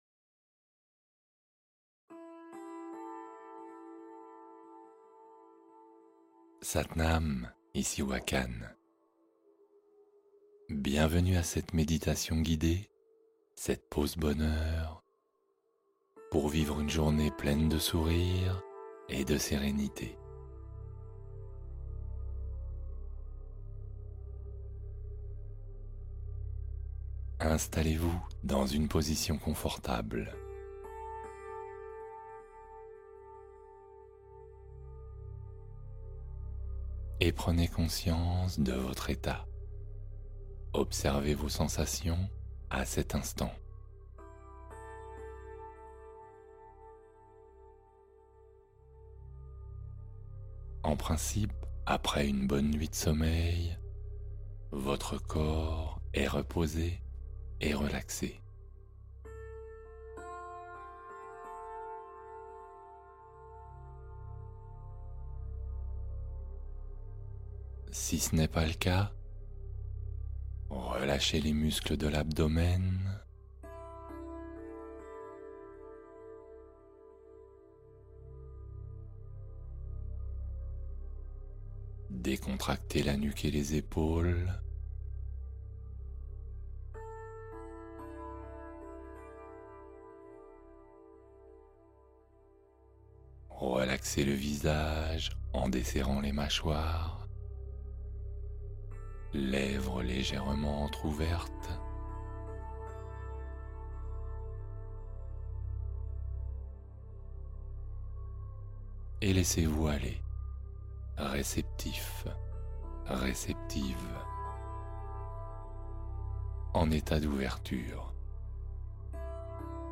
Sourire Intérieur : Méditation matinale pour illuminer votre journée